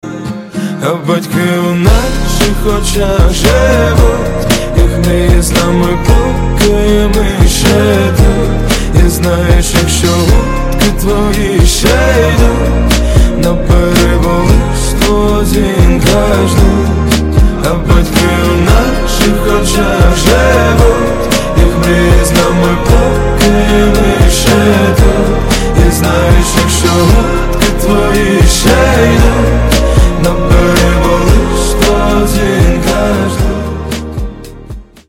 • Качество: 128, Stereo
душевные
Rap
pop-rap
R&B